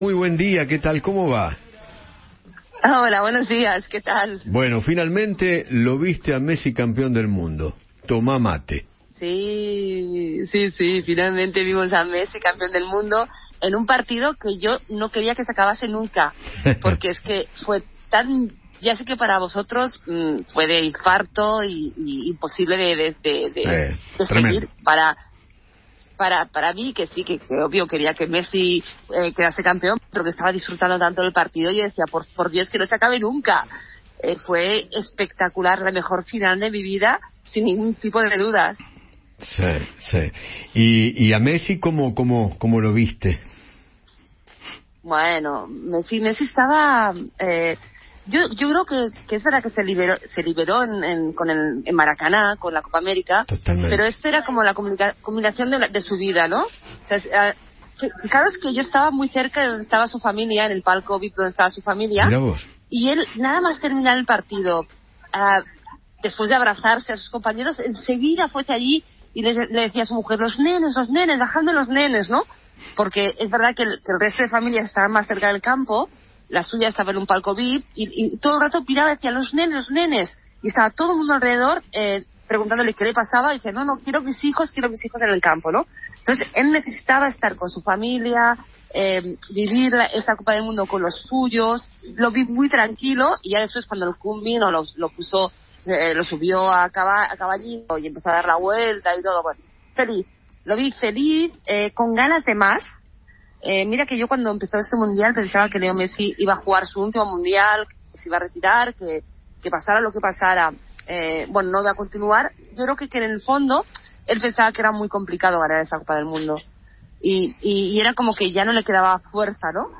conversó con Eduardo Feinmann sobre las repercusiones de los medios internacionales acerca del título de Argentina.